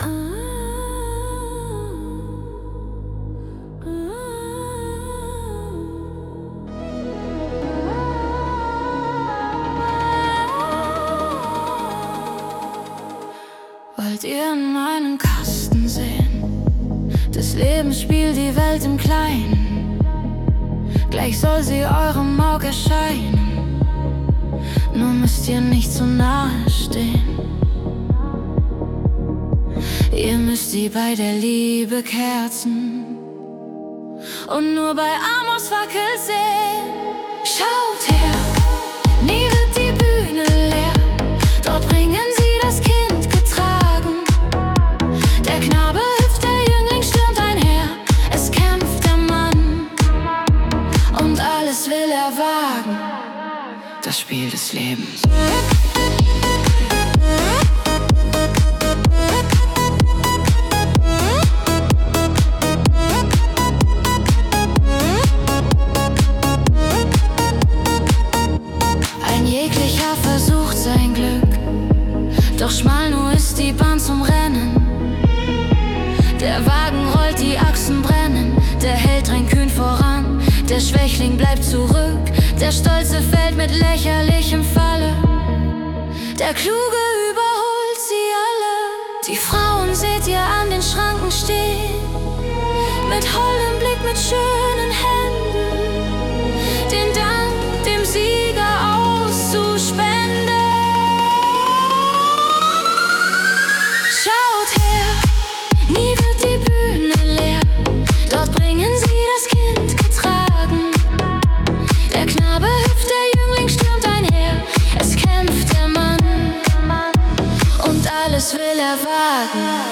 Unsere moderne Interpretation